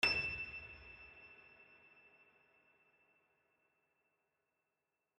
multiplayerpiano / sounds / LoudAndProudPiano / e6.mp3
e6.mp3